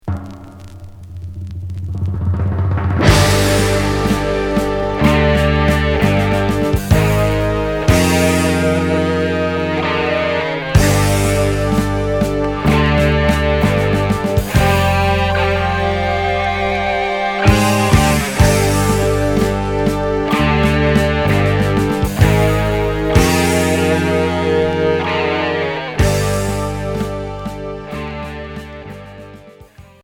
Hard progressif